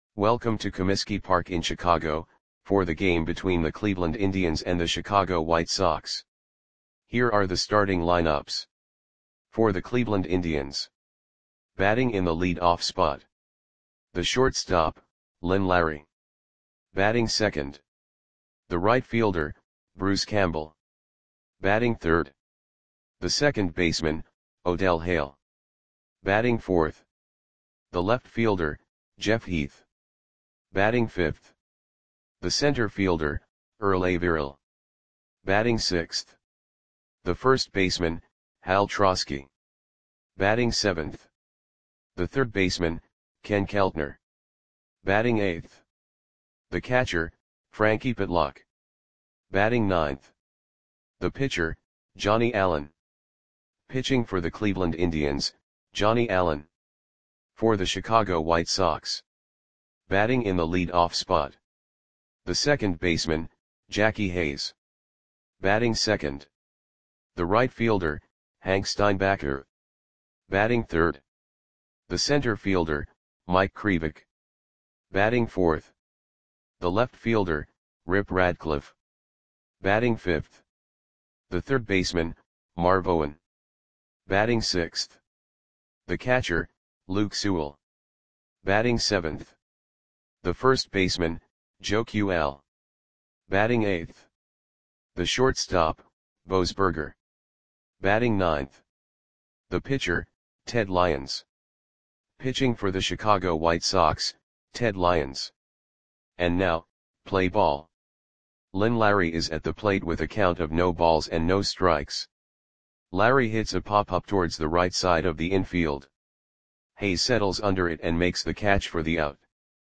Audio Play-by-Play for Chicago White Sox on July 3, 1938
Click the button below to listen to the audio play-by-play.